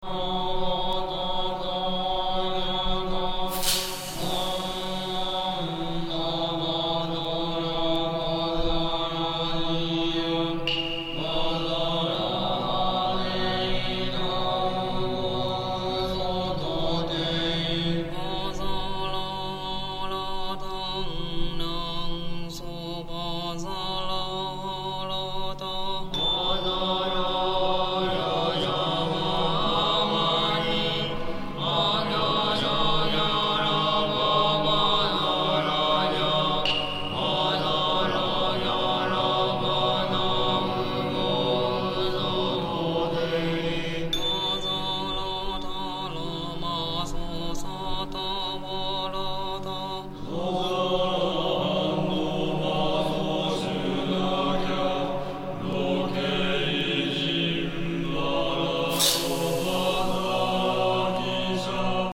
Soundtrack Ambient